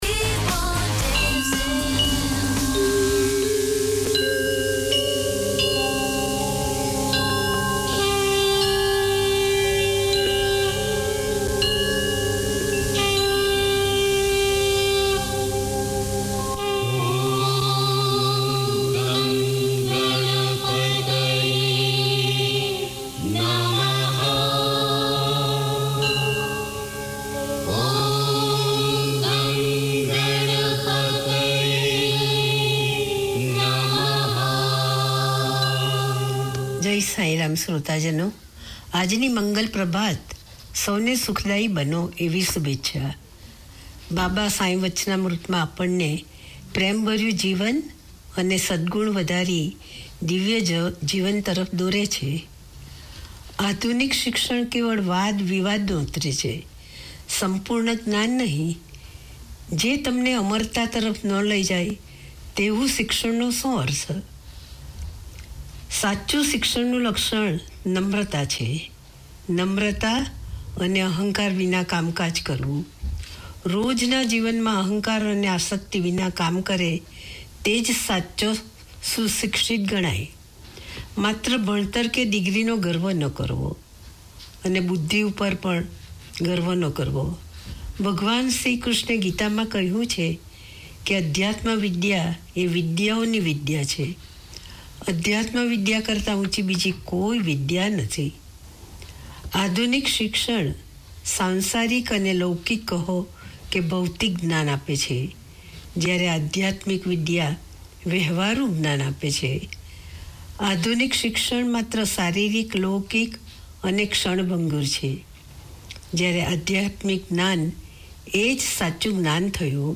Each week Sai Baba devotees can pause for ten minutes to consider the teachings of Satya Sai Baba and hear devotional songs.